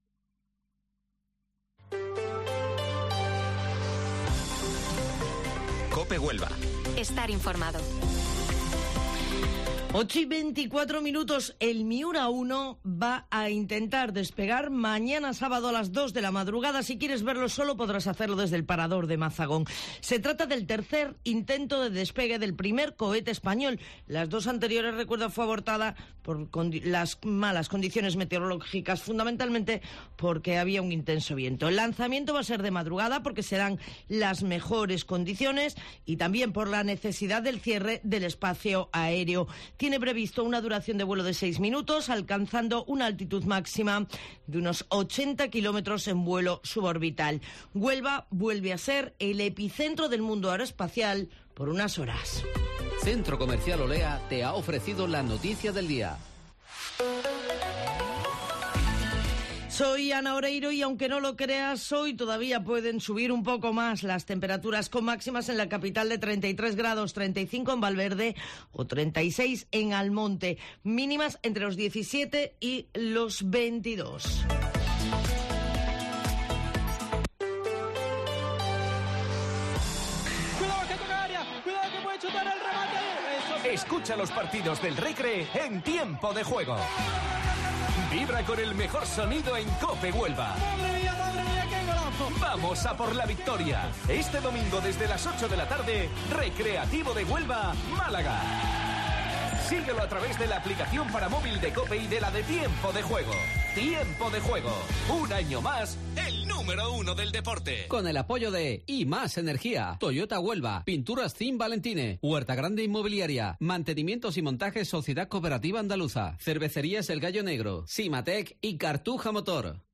Informativo Matinal Herrera en COPE 06 de octubre